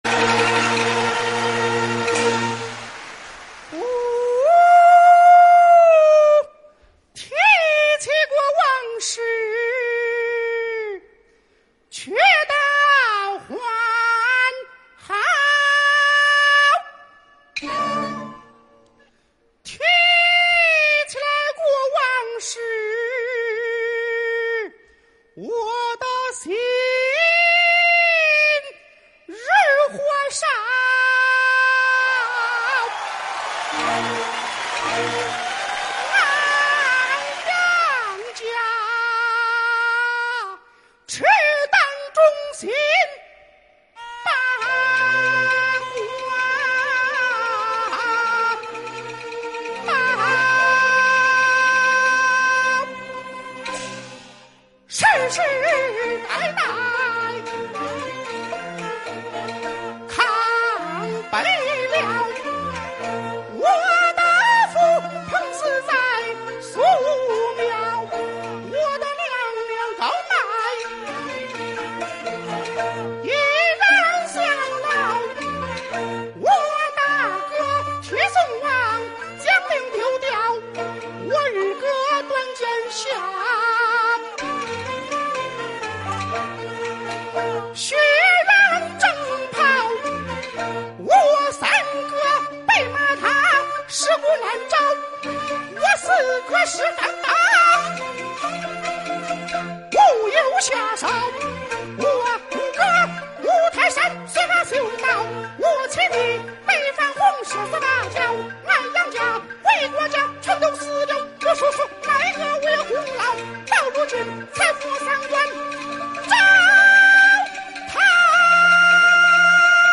豫剧